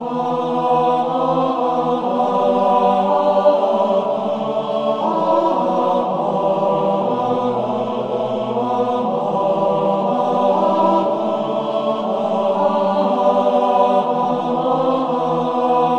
大教堂混合合唱团001
描述：合唱团的循环。
Tag: 120 bpm Electronic Loops Choir Loops 2.69 MB wav Key : Unknown